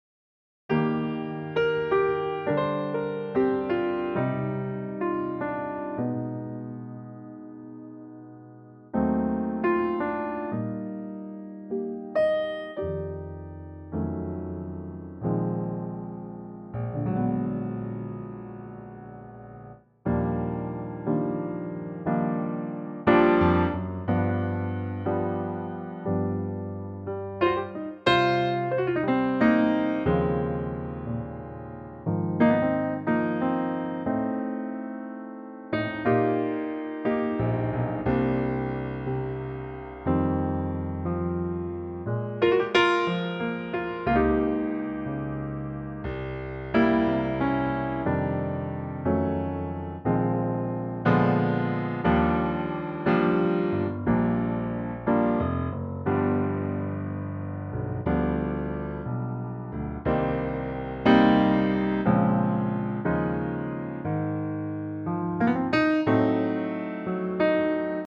Unique Backing Tracks
gorgeous piano only arrangement.